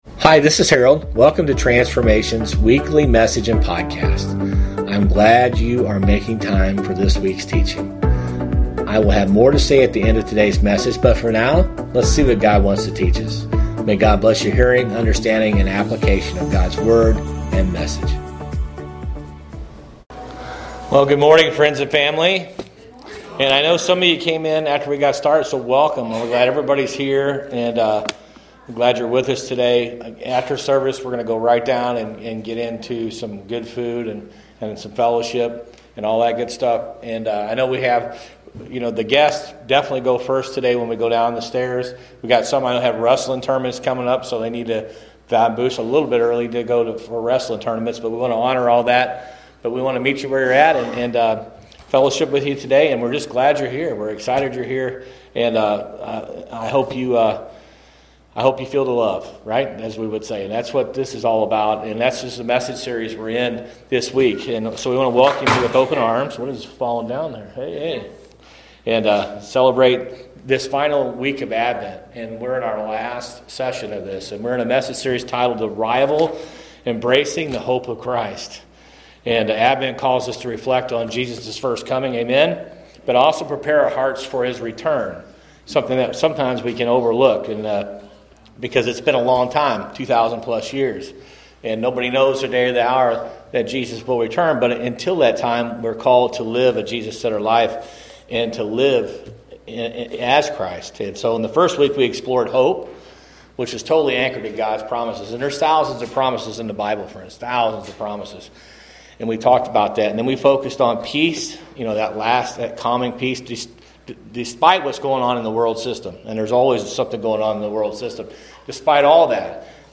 Sermons | Transformation Church